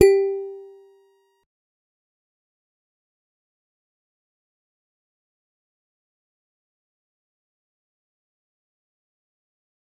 G_Musicbox-G4-mf.wav